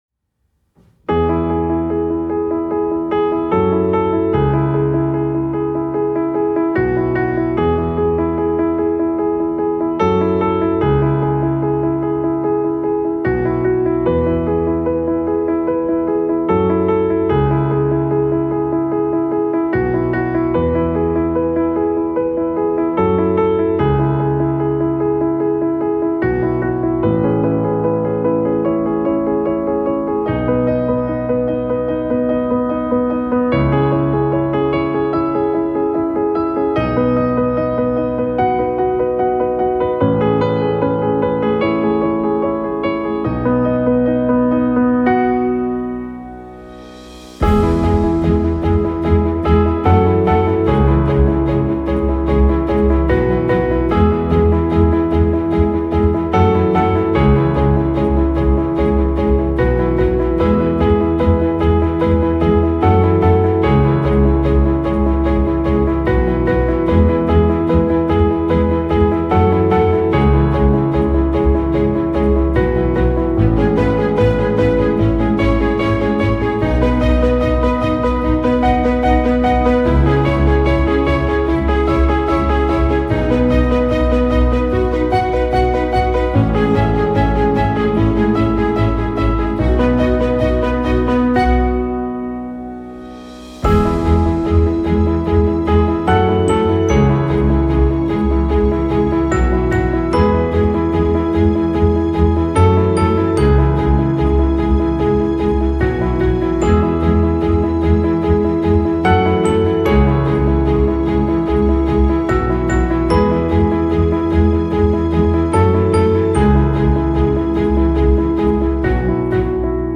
MOTIVATIONAL INSPIRING PIANO
Acoustic / Inspiring / Hopeful / Elegant